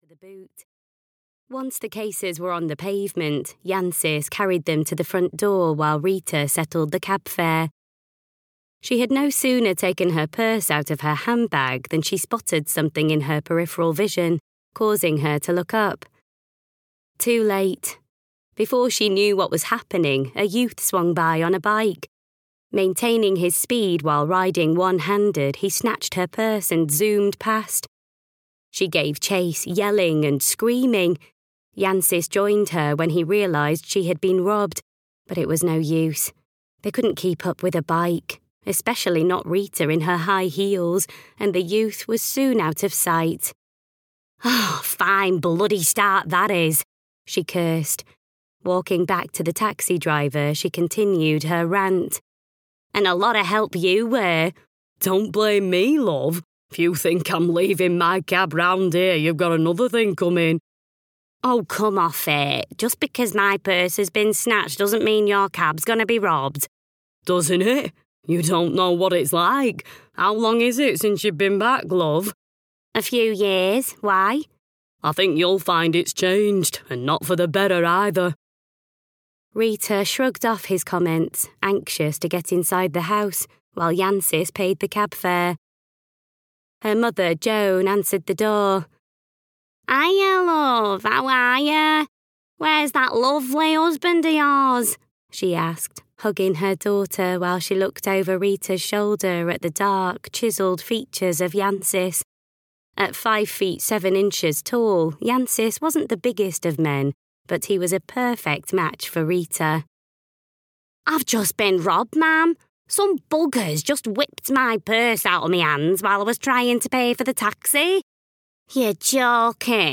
A Gangster's Grip (EN) audiokniha
Ukázka z knihy